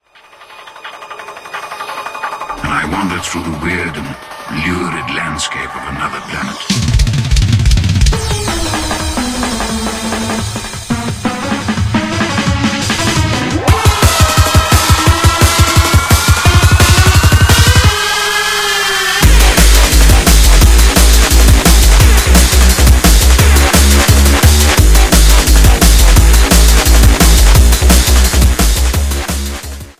Short, relatively low-quality sound sample from
This is a sound sample from a commercial recording.